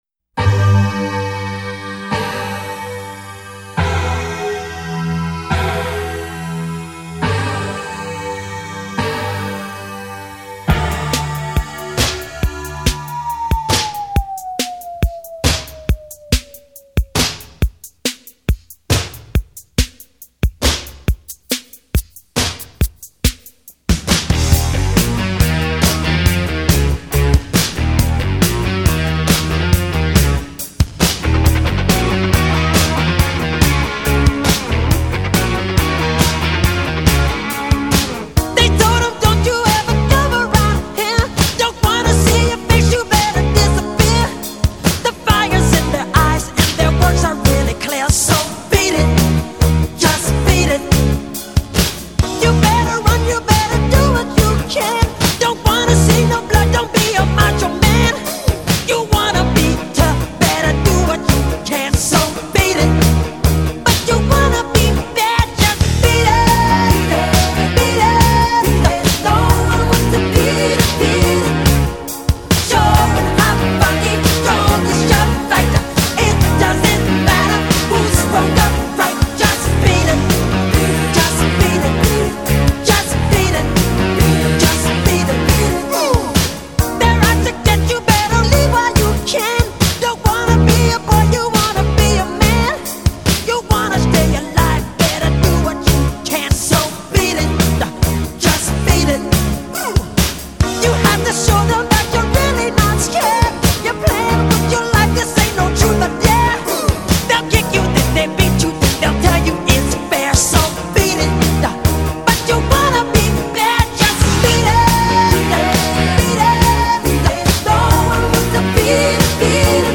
Категория : Поп